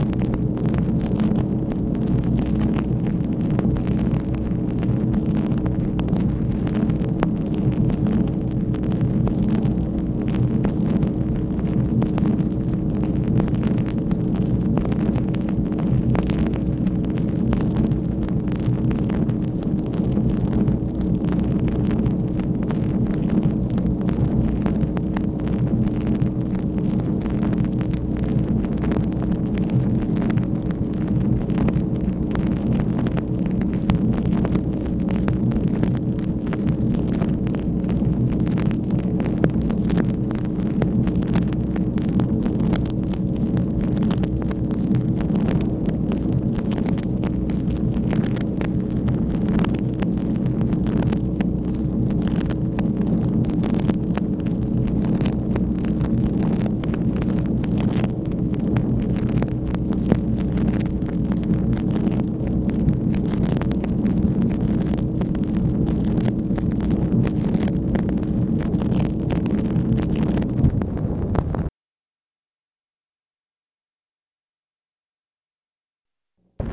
MACHINE NOISE, November 3, 1964
Secret White House Tapes